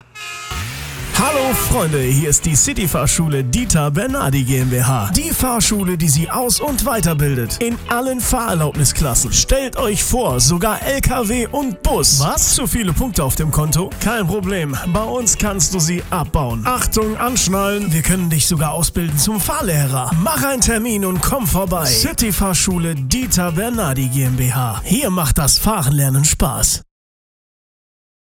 Unser Radiospot